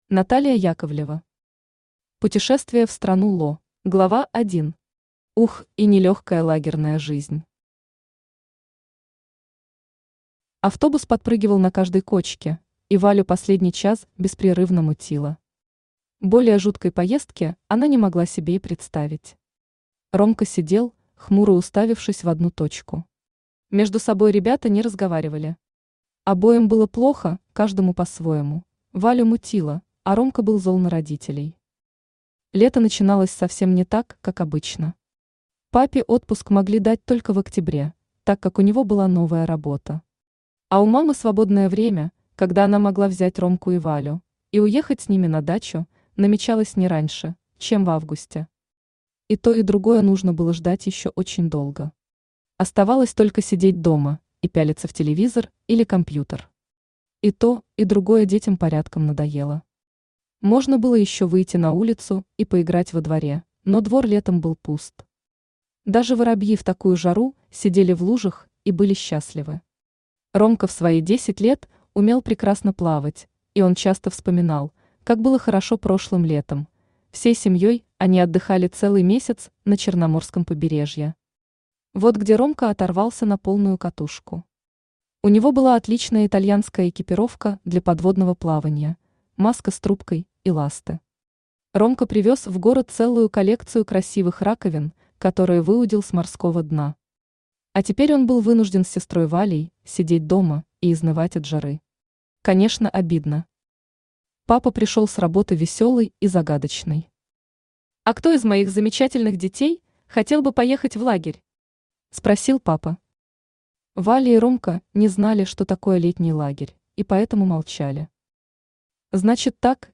Аудиокнига Путешествие в страну Ло | Библиотека аудиокниг
Aудиокнига Путешествие в страну Ло Автор Наталия Яковлева Читает аудиокнигу Авточтец ЛитРес.